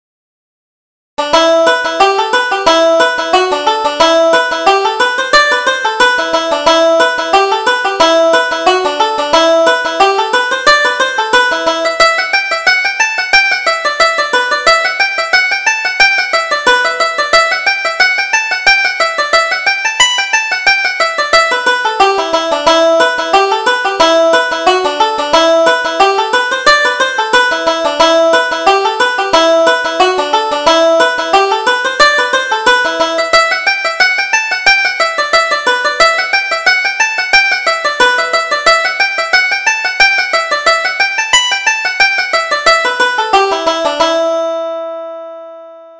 Reel: The Man of the House